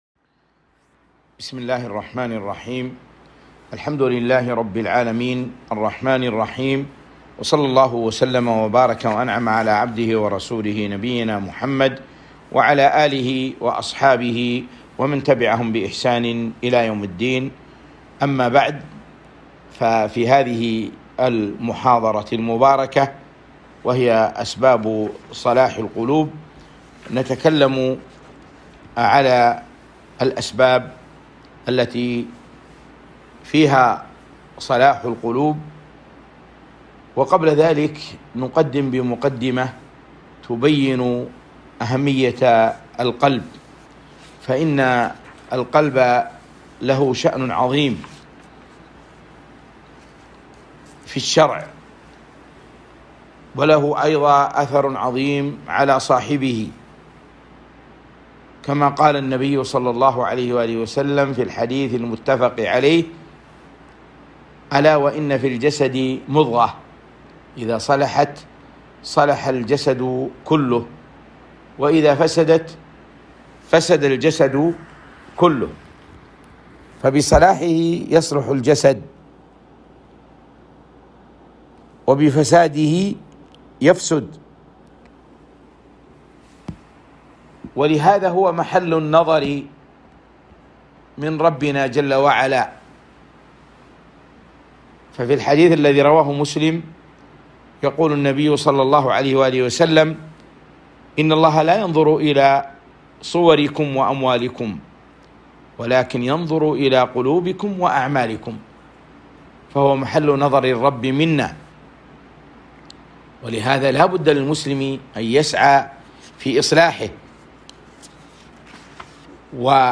محاضرة - أسباب صلاح القلوب